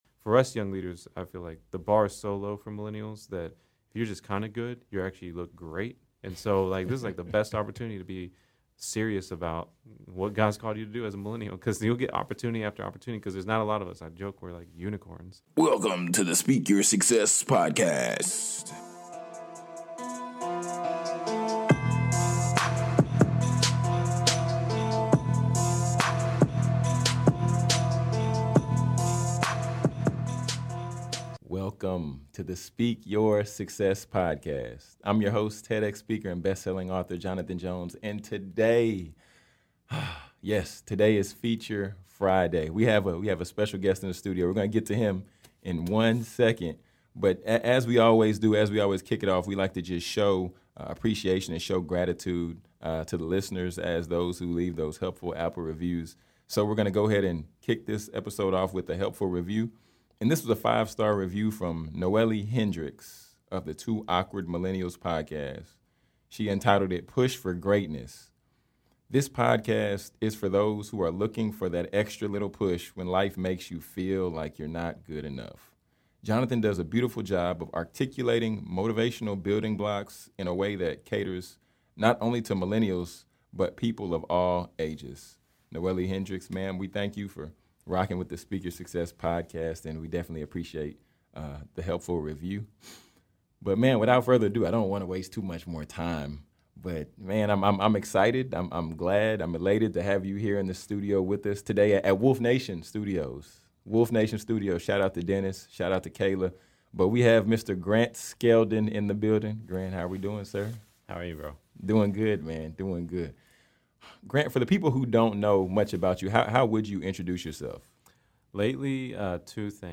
We had an amazing time in the studio with a true Millennial leader.